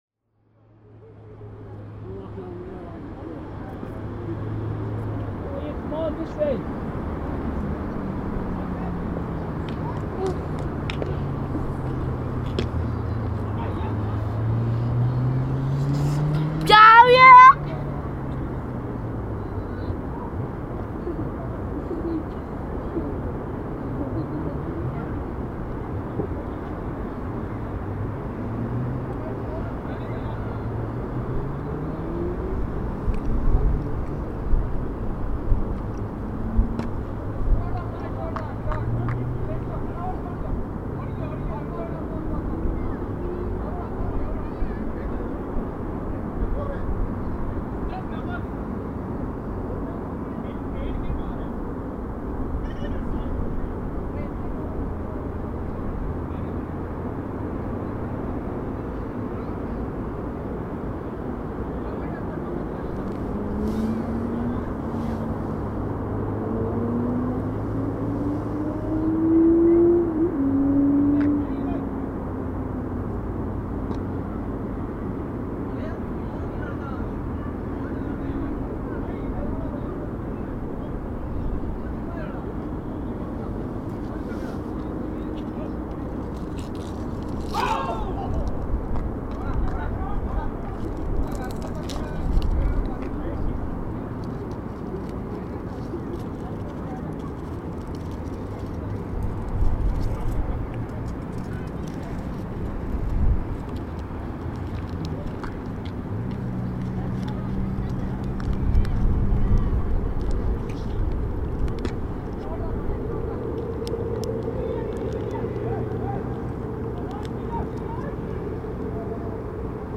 It was not easy to find fine, rhythmic, and multilayered sounds in the permanent noise of engines and voices – which sometimes appeared to me as a mixture of annoying and disturbing frequences.
The park, too, though, was not quiet. The noise of the city left no room for silence.
phoenix_park-na.mp3